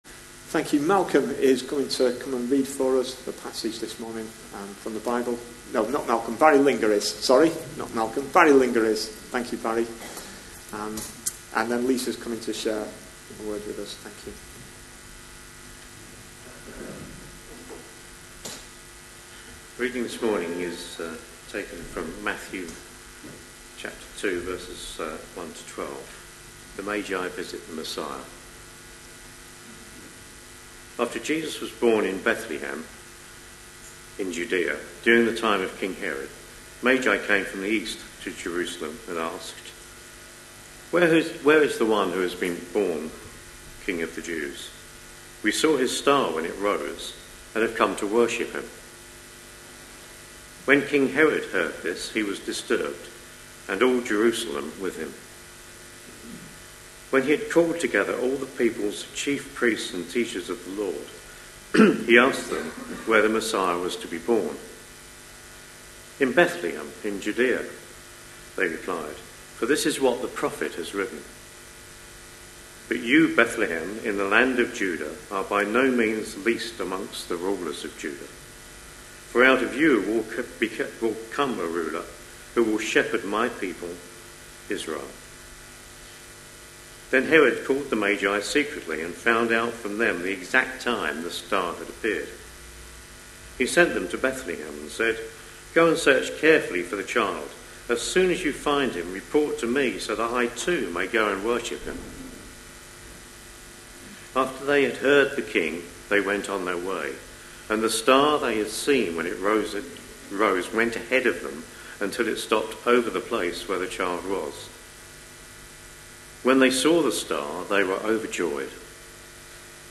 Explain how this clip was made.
Matthew 2:1-12 Listen online Details Reading is Matthew 2:1-12 ("The Visit of the Magi", NIV), with a reference to Micah 5.2 which is quoted in the reading. (Slight interference on sound at the start.) This was the second Sunday in Advent.